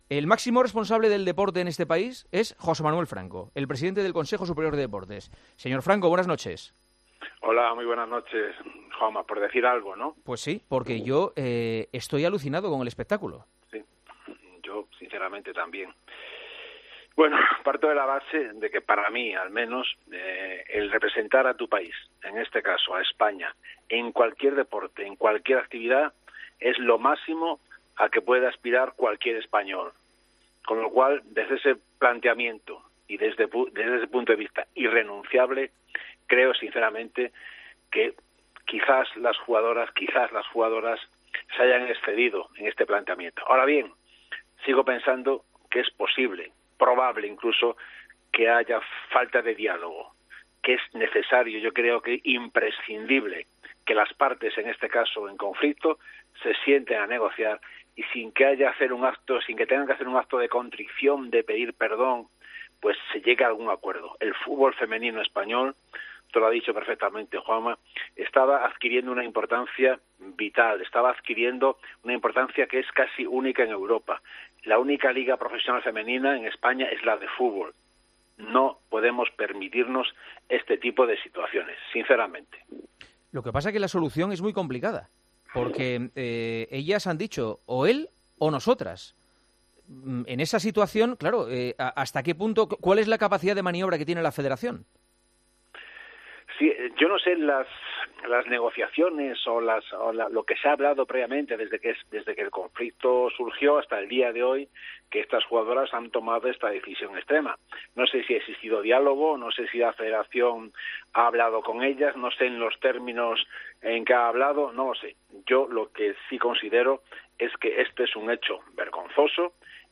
AUDIO - ENTREVISTA A JOSÉ MANUEL FRANCO, EN EL PARTIDAZO DE COPE